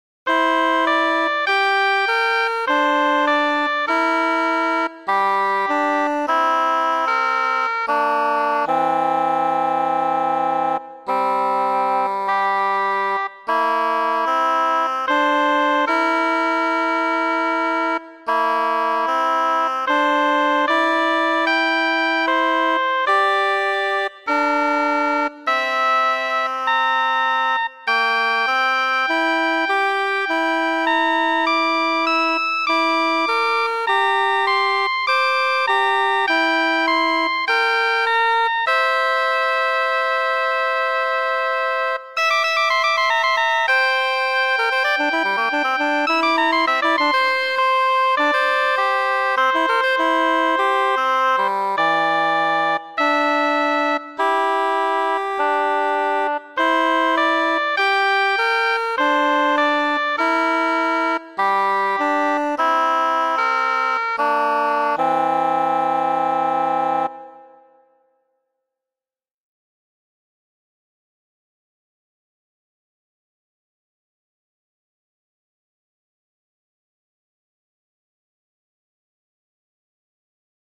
Microtonal Compositions retuned with Tune Smithy - with occasional 10 ms delays
(midi clips) Or click on title for the audio recording (rendered with Roland Sound Canvas)